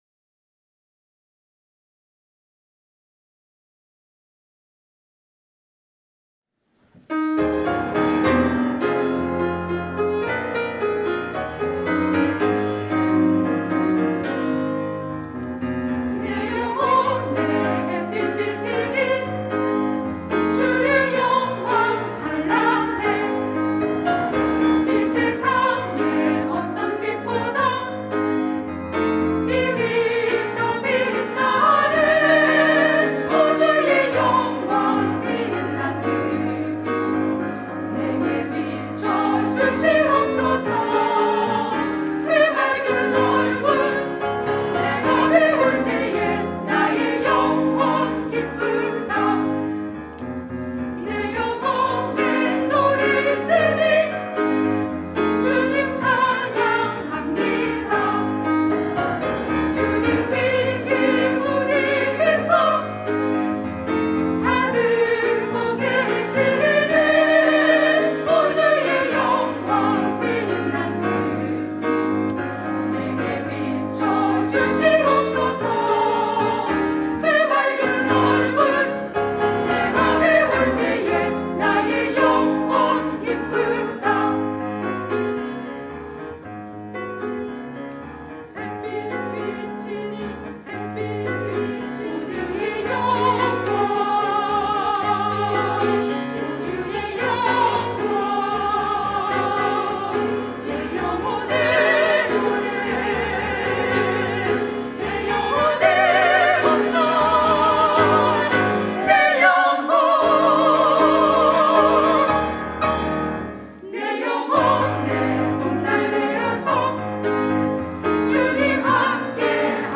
샤론